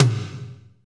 Index of /90_sSampleCDs/Northstar - Drumscapes Roland/DRM_Slow Shuffle/KIT_S_S Kit 2 x
TOM S S M11L.wav